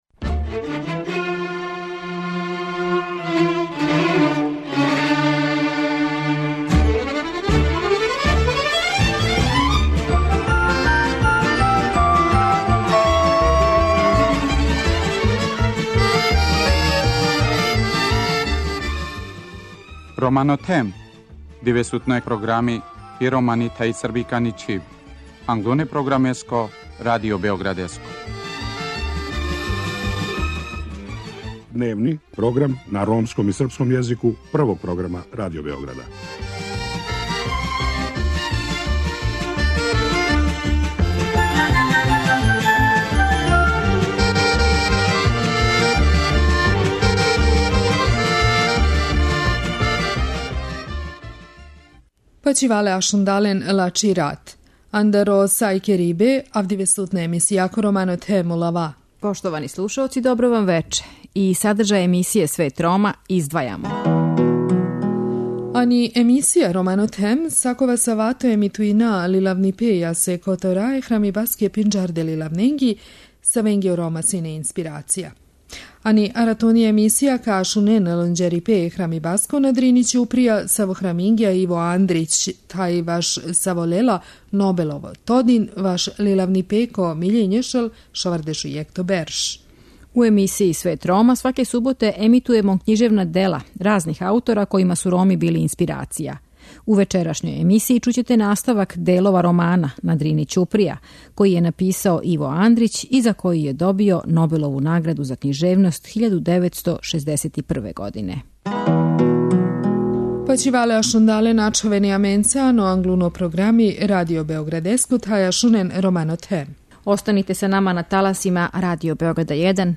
У вечерашњој емисији чућете наставак делова романа "На Дрини ћуприја", који је написао Иво Андрић и за који је добио Нобелову награду за књижевност 1961. године.